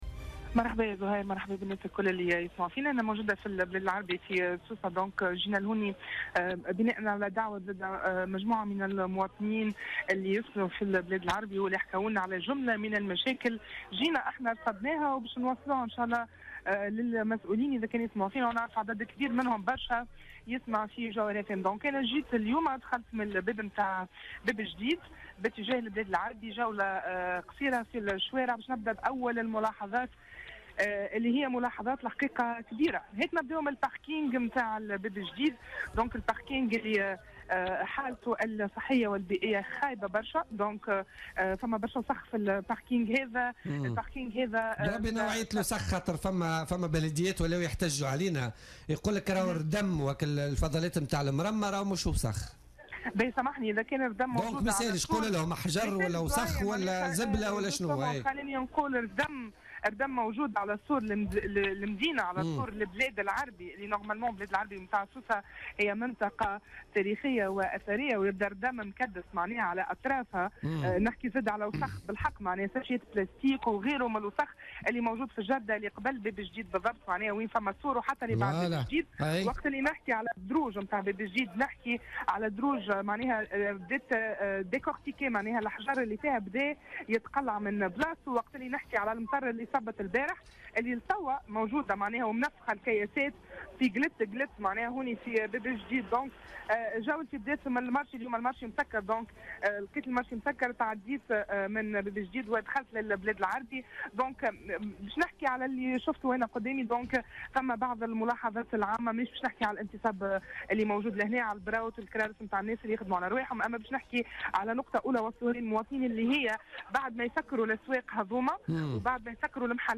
تنقل الرادار اليوم الثلاثاء 6 ديسمبر 2016 إلى المدينة العتيقة بسوسة بدءا من باب الجديد إلى داخل المدينة العتيقة.